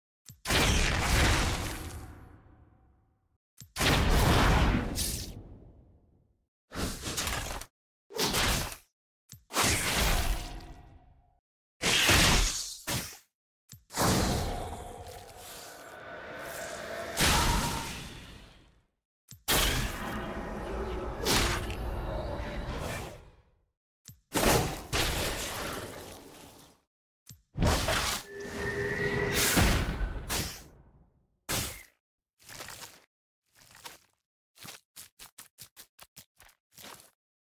💥 Ult Sounds, Voice, & More 💥